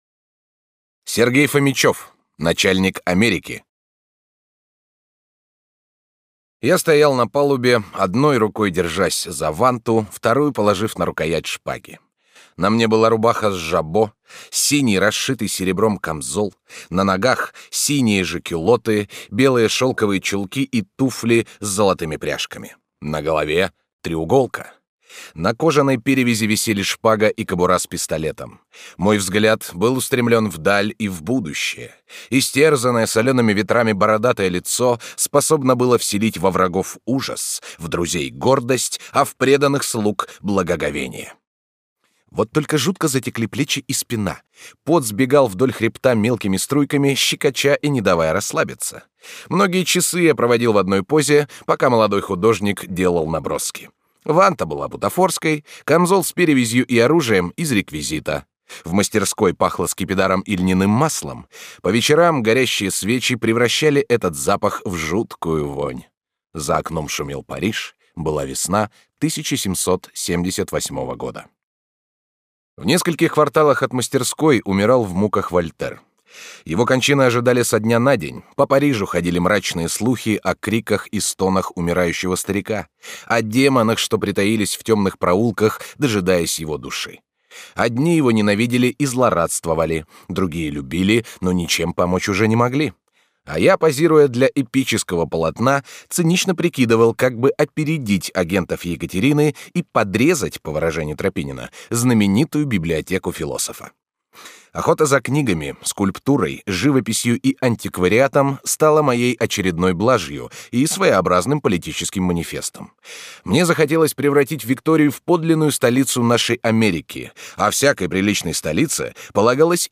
Аудиокнига Начальник Америки | Библиотека аудиокниг
Прослушать и бесплатно скачать фрагмент аудиокниги